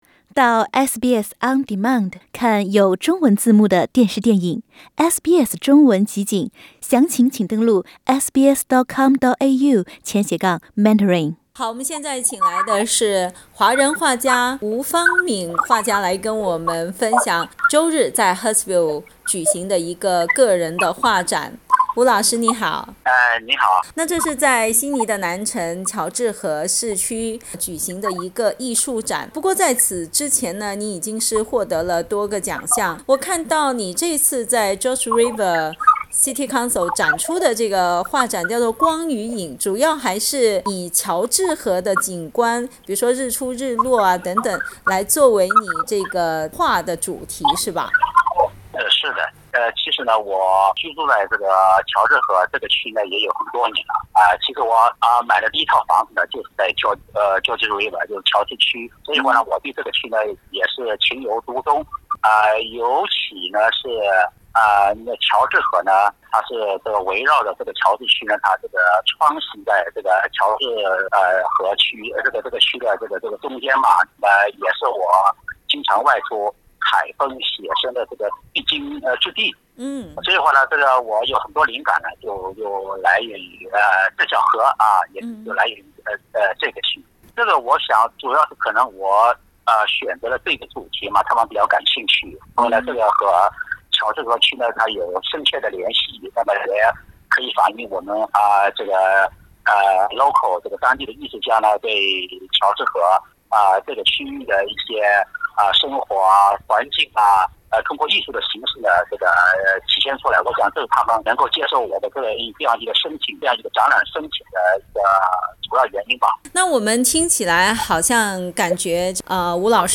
（请听采访） 澳大利亚人必须与他人保持至少1.5米的社交距离，请查看您所在州或领地的最新社交限制措施。